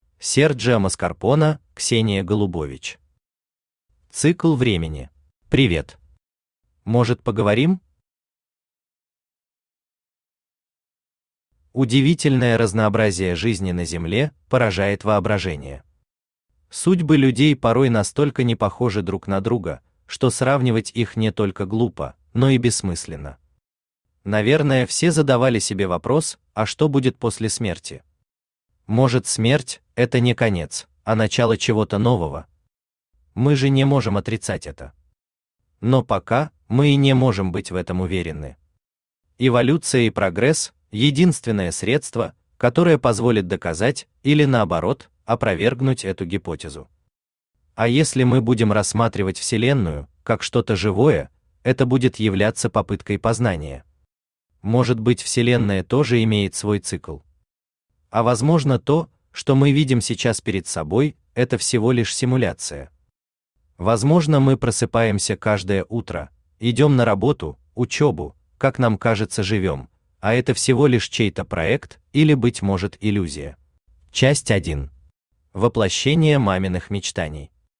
Аудиокнига Цикл времени | Библиотека аудиокниг
Aудиокнига Цикл времени Автор Серджио Маскарпоне Читает аудиокнигу Авточтец ЛитРес.